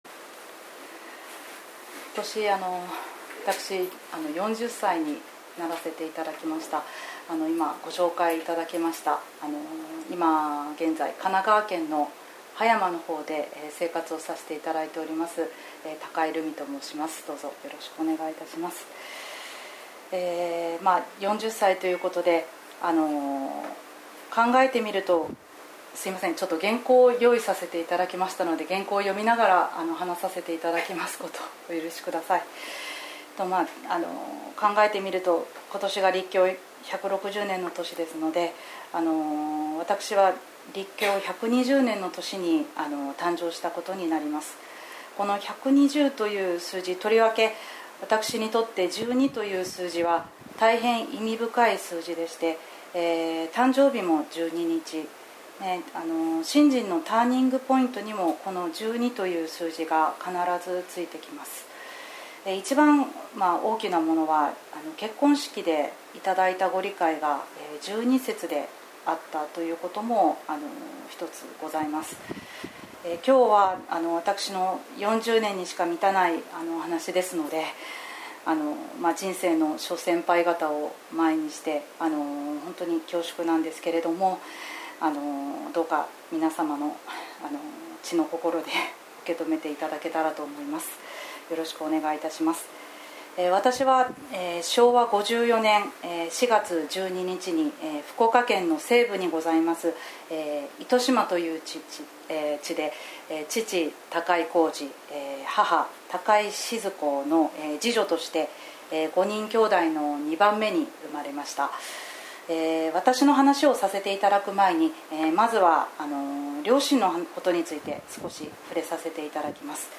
19.05.18 月例祭感話発表(女性信徒)
投稿ナビゲーション 過去の投稿 前 御信徒による感話発表